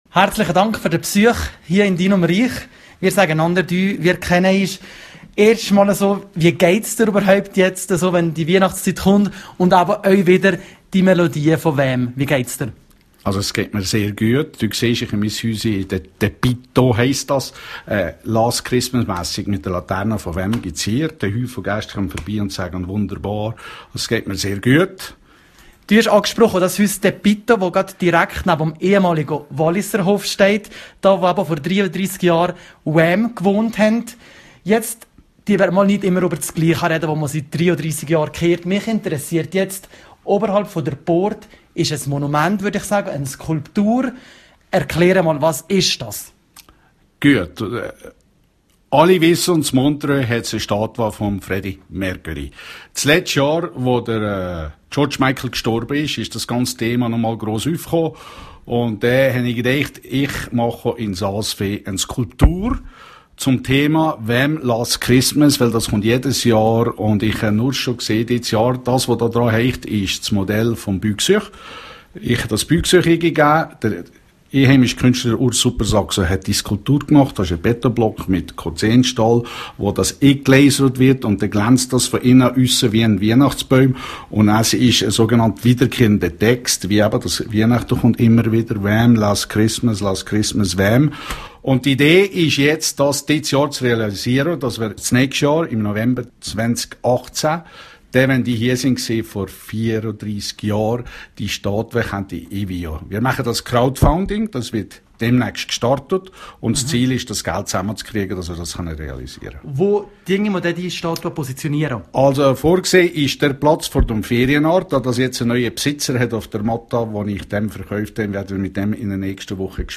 das ganze Interview